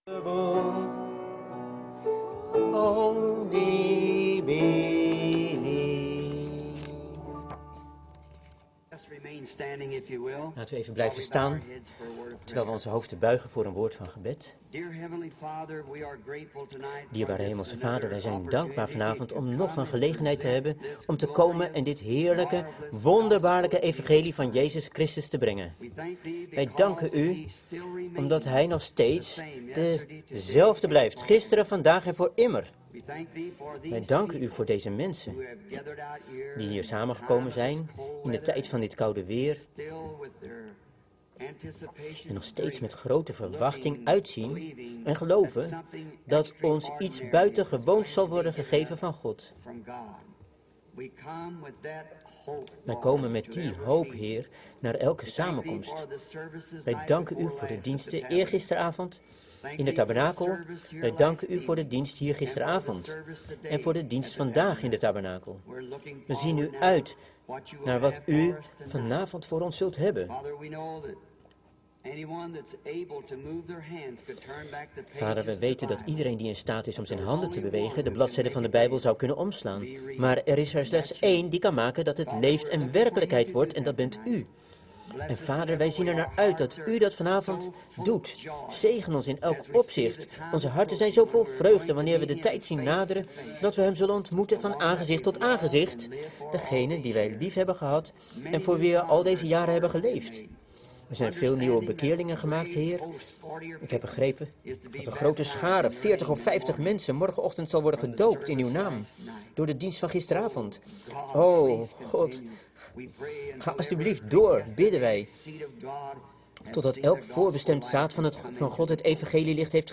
Prediking
Locatie Parkview junior high school Jeffersonville , IN